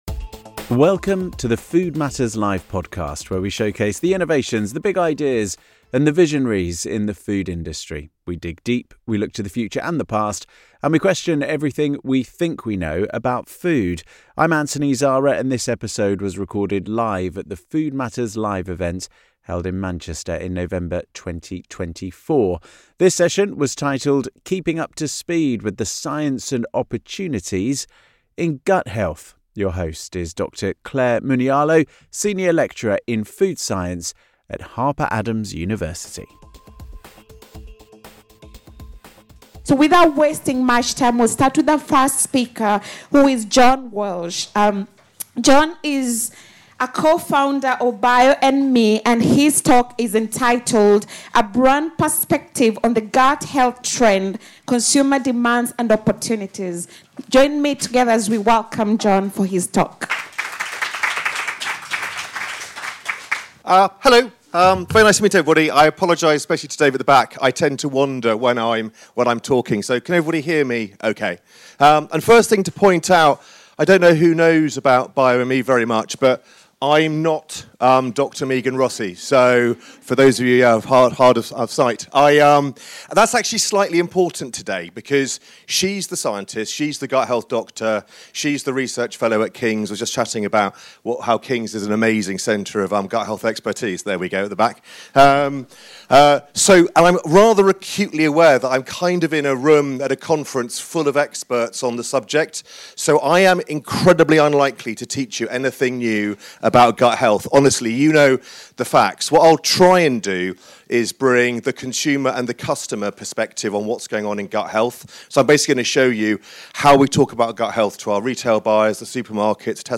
In this episode of the Food Matters Live podcast, recorded live in Manchester in November 2024, our panel of experts delve into the topic and how the food industry is responding to increased consumer demand.